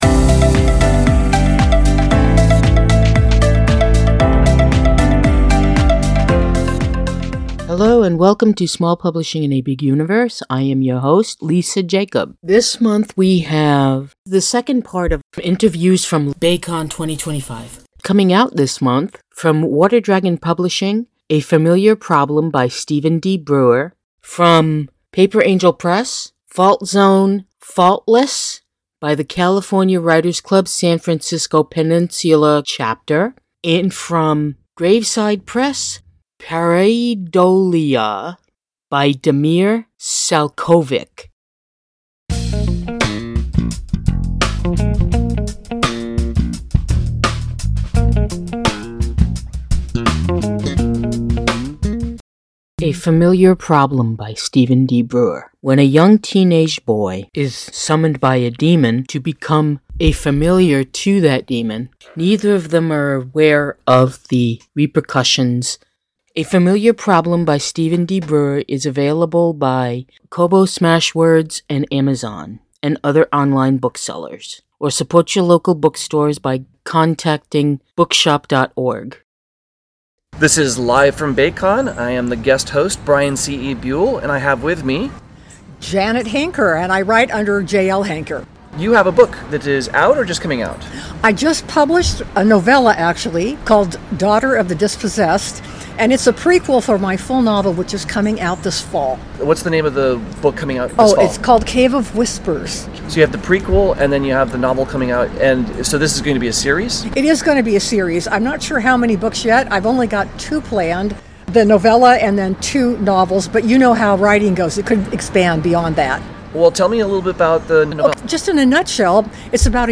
We are LIVE from Baycon 2025 / Westercon 77 from July 4, 2025 – July 7, 2025 in Santa Clara, California.